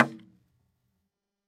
Arrow Release
A bow string releasing an arrow with twang, shaft vibration, and whooshing flight
arrow-release.mp3